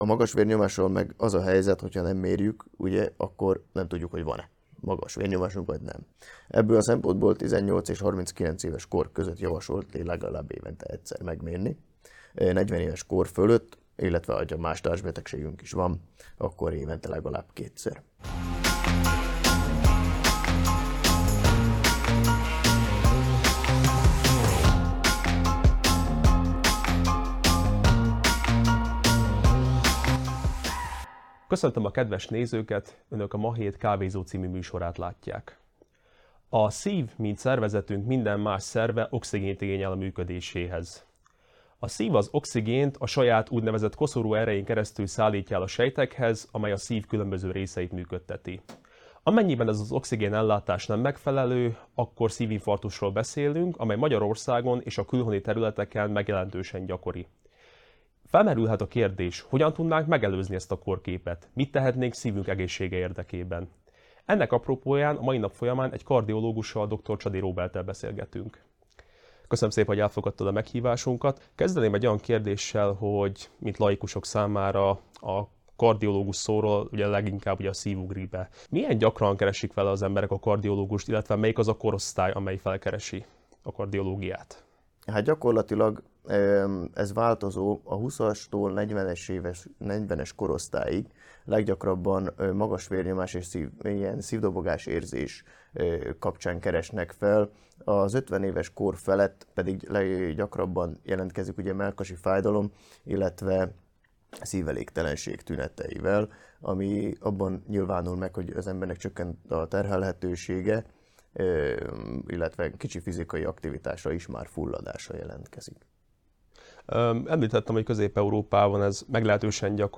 A kávézó legújabb adásában egy kardiológussal beszélgettünk "szívügyeinkről".